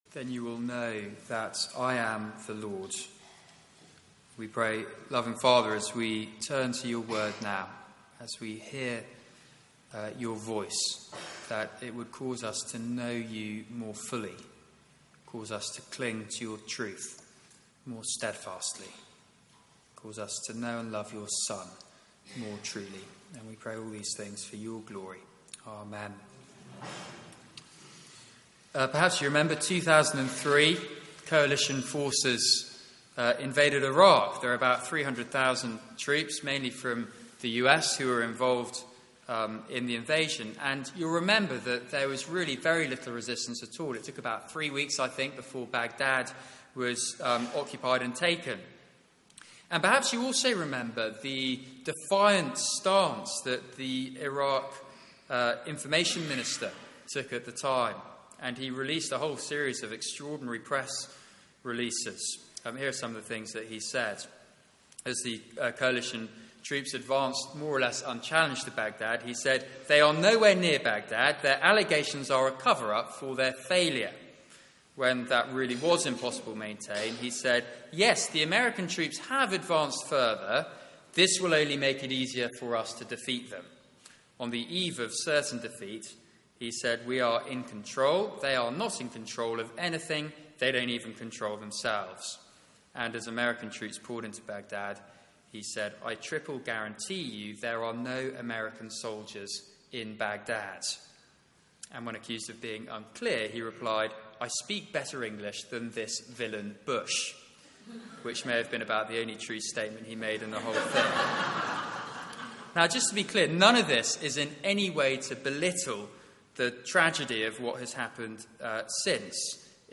Media for 9:15am Service on Sun 03rd Jan 2016
Passage: Ezekiel 13 Series: Ezekiel - Hope for the Hopeless Theme: Falsehood Sermon